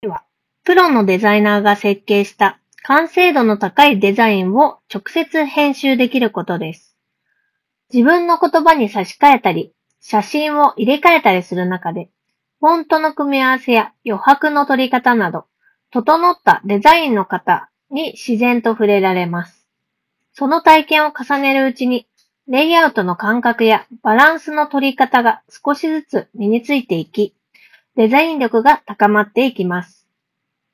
ノイキャン効果は高く、周囲のノイズを効果的に取り除き、装着者の声のみをクリアに拾い上げることができていました。
▼OpenRock S2で収録した音声
iPhone 16 Proで撮影した動画と、イヤホン内蔵マイクで収録した音声を聴き比べてみると、内蔵マイクでは周囲の環境ノイズ（空調音、本に触れる音）などが効果的に低減されており、装着者の声のみを明瞭に拾い上げることができています。
さすがに専用のハイエンドマイクと比べると録音品質は劣るものの、普通に通話するには十分に優れた性能となっています。特に装着者の発言内容が強調されており、聴き取りやすいです。
openrock-s2-review.wav